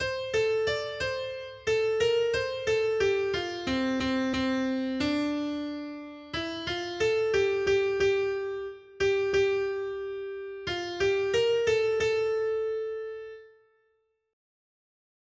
Noty Štítky, zpěvníky ol42.pdf responsoriální žalm Žaltář (Olejník) 42 Ž 128, 1-5 Skrýt akordy R: Blaze každému, kdo se bojí Hospodina. 1.